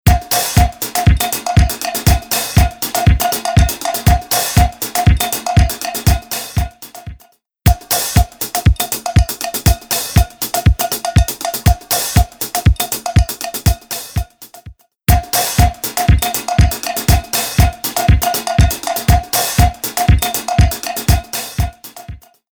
Tube Amp-style Spring Reverb
Spring | Drums | Preset: Space Heater
Spring-Eventide-Drums-Space-Heater.mp3